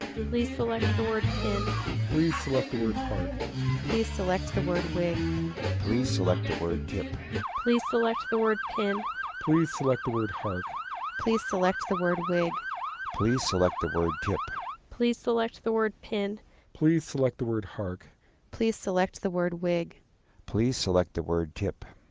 • EVS — 3GPP Enhanced Voice Services Codec.
• Wideband — Supported audio bandwidth has a nominal upper limit between 7 and 8 kHz.
• Club — Speech combined with recorded nightclub noise at 0 dB signal-to-noise ratio (SNR).
• Siren — Speech combined with recorded fire truck siren at 0 dB SNR.
• All — Contains club, siren, and quiet versions, in that order.
• Female speaker — “Please select the word pin.”
• Male speaker — “Please select the word hark.”